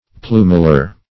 plumular - definition of plumular - synonyms, pronunciation, spelling from Free Dictionary Search Result for " plumular" : The Collaborative International Dictionary of English v.0.48: Plumular \Plu"mu*lar\, a. (Bot.) Relating to a plumule.